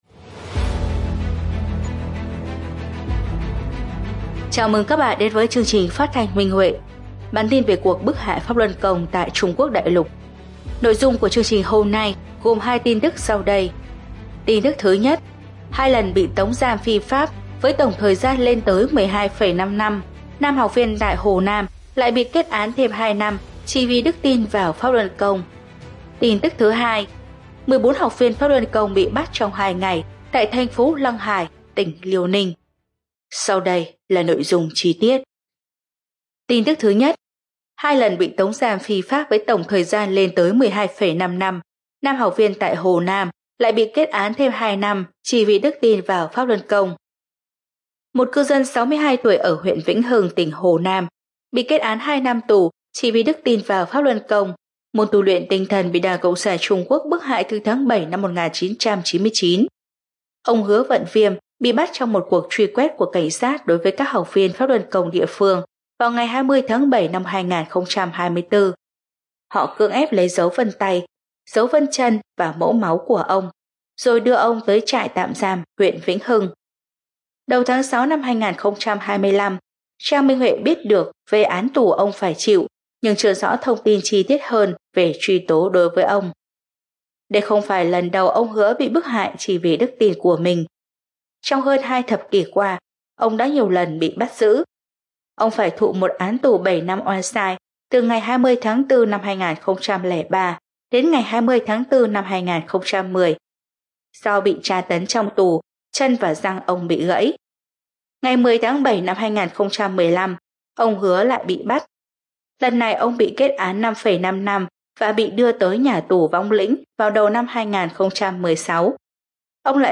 Chương trình phát thanh số 214: Tin tức Pháp Luân Đại Pháp tại Đại Lục – Ngày 10/6/2025